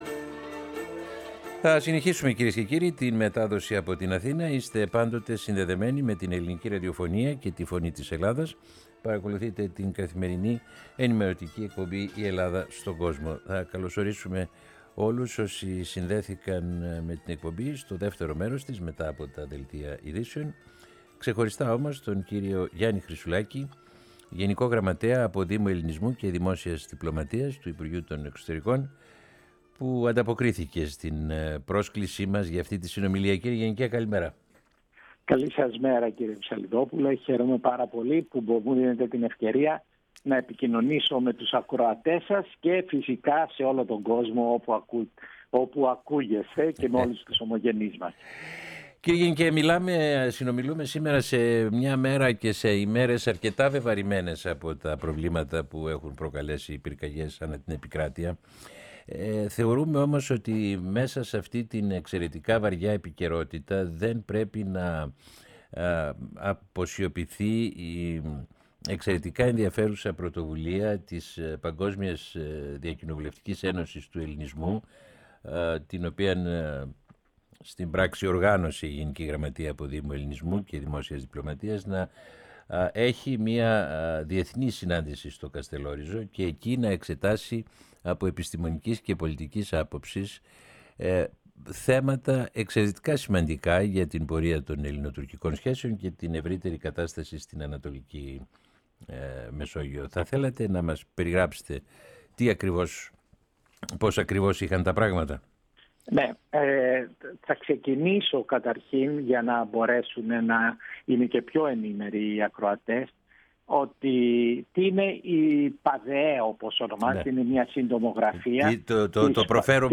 Ο ΓΓ Απόδημου Ελληνισμού και Δημόσιας Διπλωματίας, Γιάννης Χρυσουλάκης, σε συνέντευξή του στη Φωνή της Ελλάδας